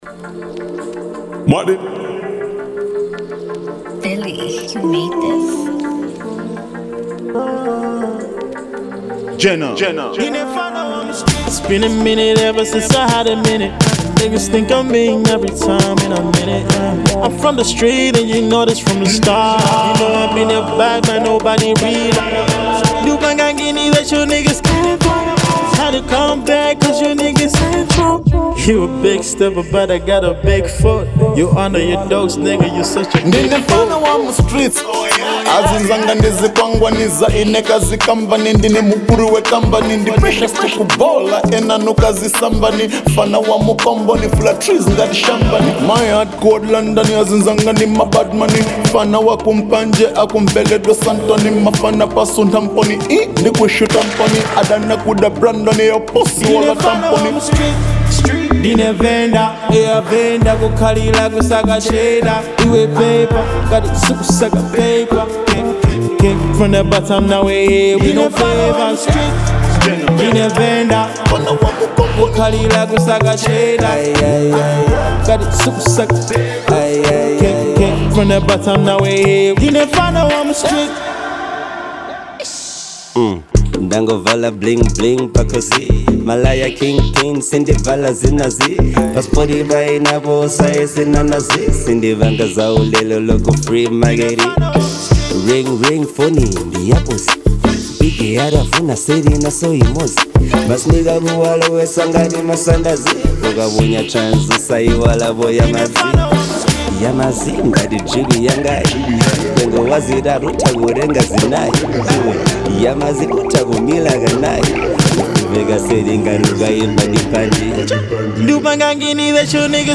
Afro R&B/Dancehall